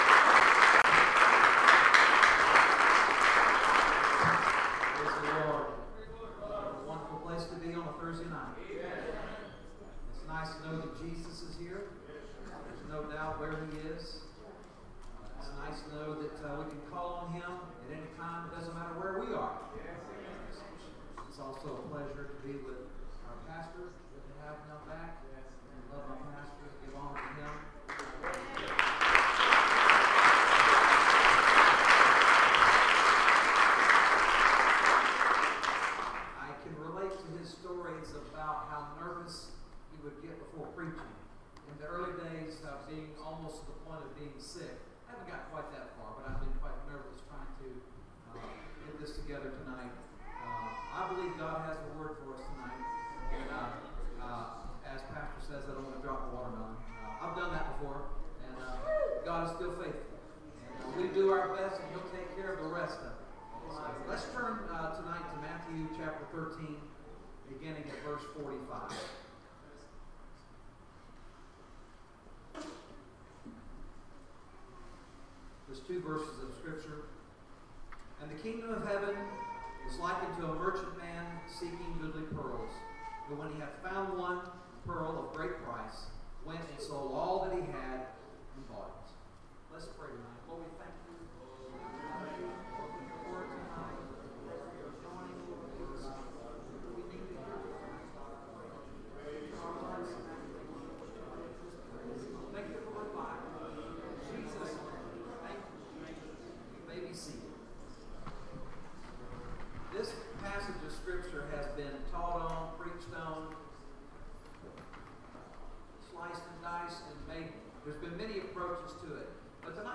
First Pentecostal Church Preaching 2019